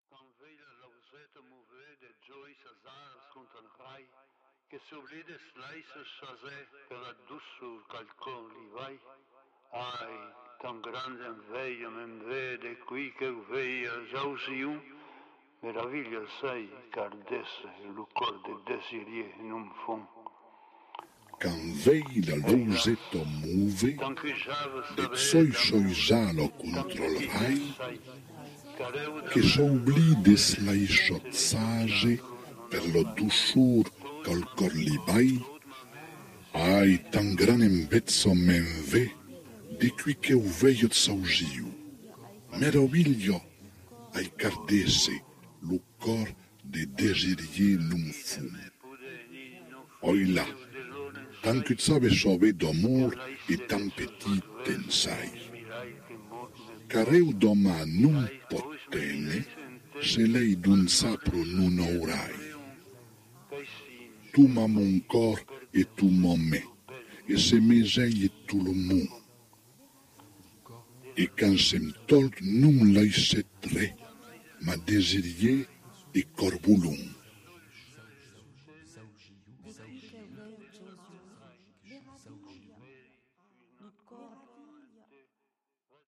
cansos dels trobadors /